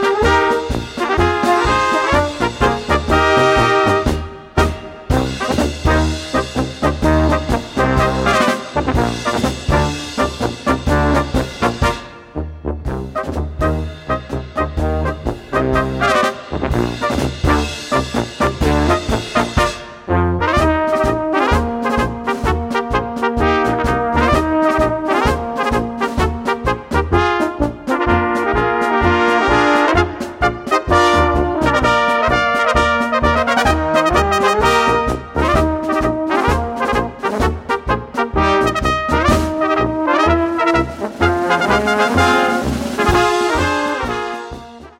Gattung: für Tenohorn und Bariton in B
Besetzung: Instrumentalnoten für Tenorhorn